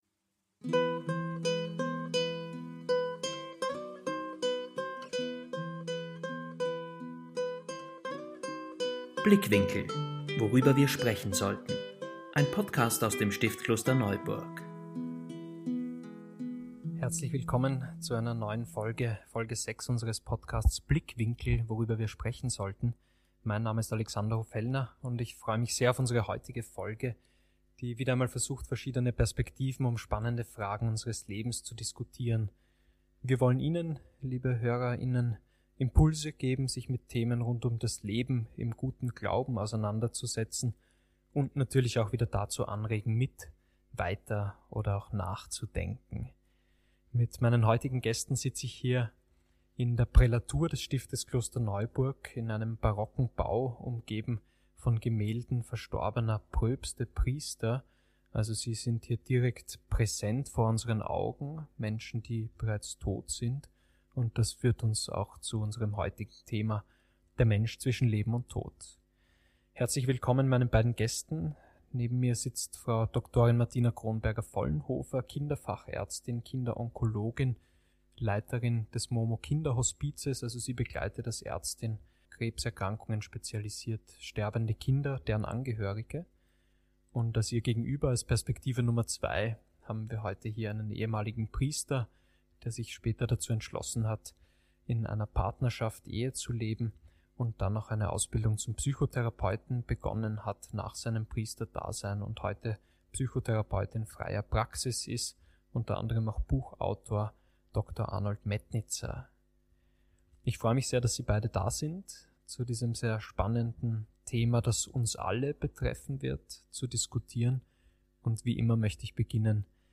In einem spannenden Gespräch lassen uns die beiden Gäste an Erlebnissen und Geschichten aus ihrem beruflichen wie auch persönlichen Leben teilhaben und beleuchten den Tod aus unterschiedlichen Perspektiven und Biografien.